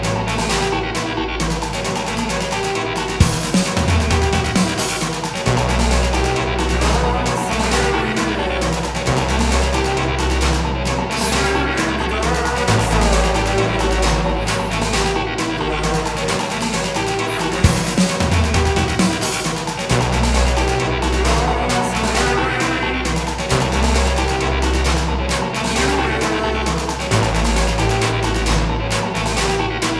une musique pop aux contours complexes